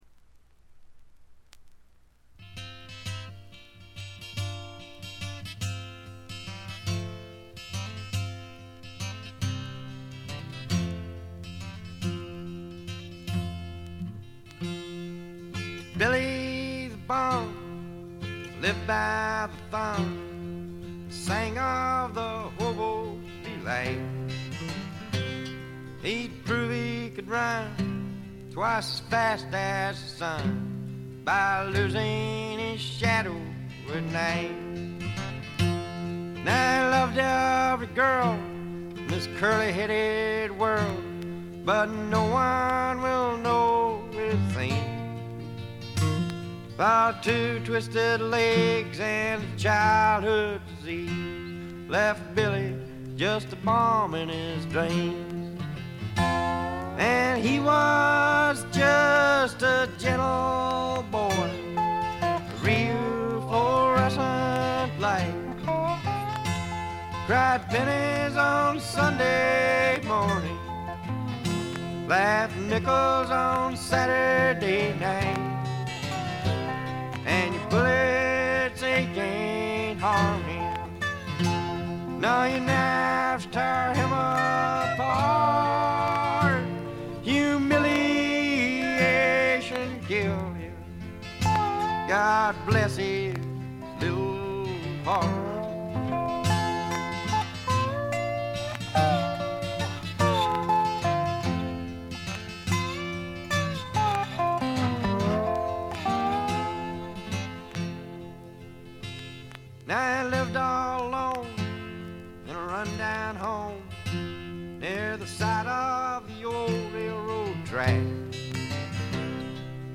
バックグラウンドノイズ、チリプチ多め大きめ。ところどころで散発的なプツ音。
アパラチアのマウンテンミュージックに根ざしたアコースティックなサウンドが心地よいです。
試聴曲は現品からの取り込み音源です。
mandolin, fiddle, banjo, vocals